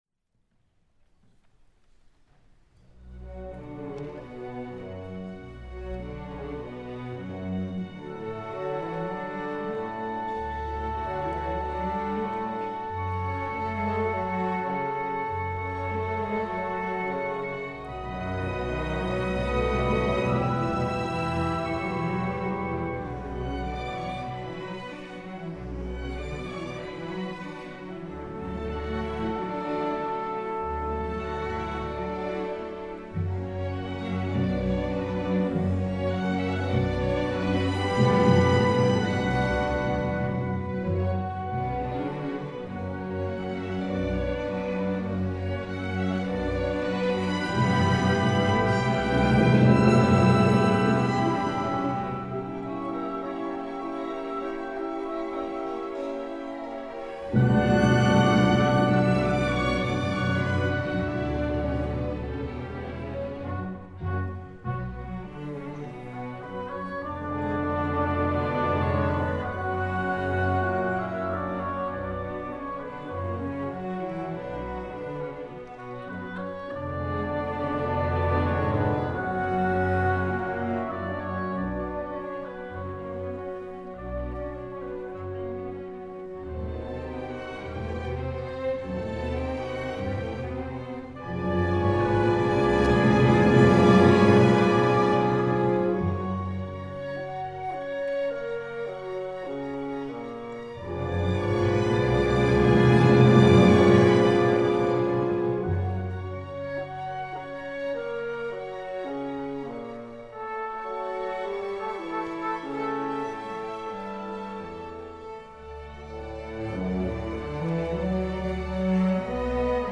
Langue Français Fecha: Mercredi, 4 mars, 2020 Duración: 10:31 Audio: mendelssohn040320.mp3 Temporada: Temporada 2019-2020 Audio promocionado: Autor: Felix Mendelssohn Categoría: Saison Symphonique ¿Pertenece a algún disco?: